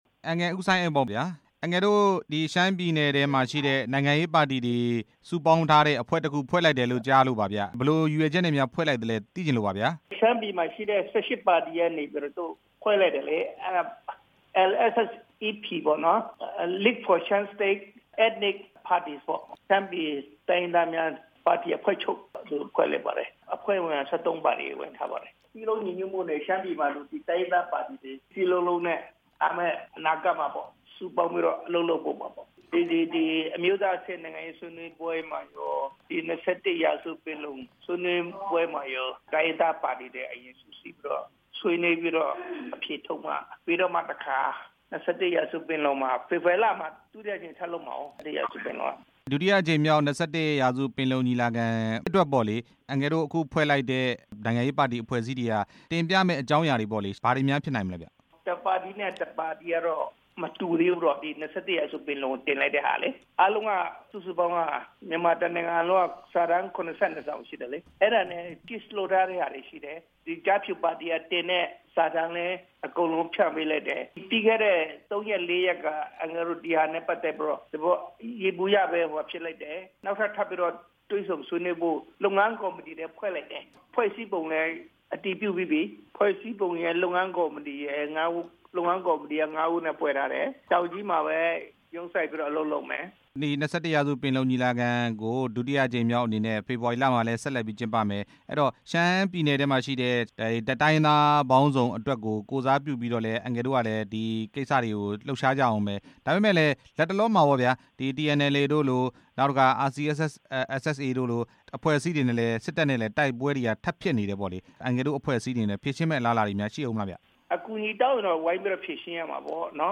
ရှမ်းပြည်နယ် တိုင်းရင်းသားပါတီများ အဖွဲ့ချုပ် အကြောင်း မေးမြန်းချက်